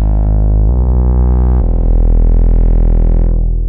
Inifinite Sadness Ab 130.wav